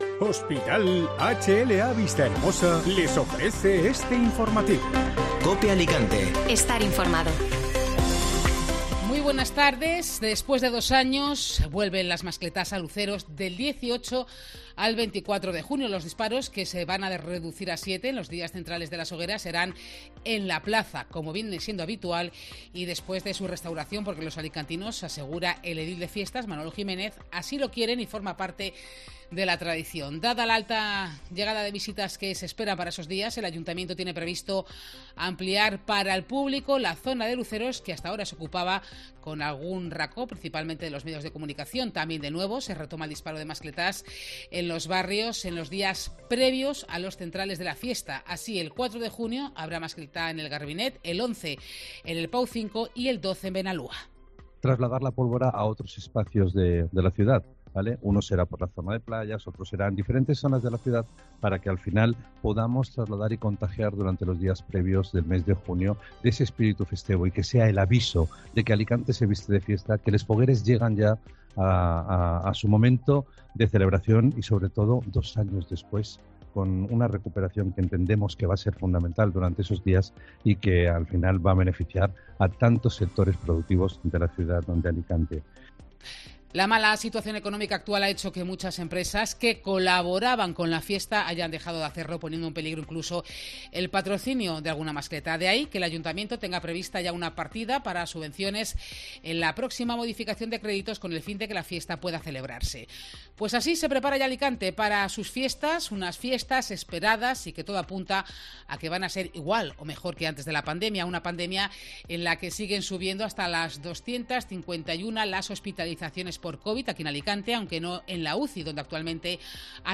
Informativo Mediodía COPE (Miércoles 18 de mayo)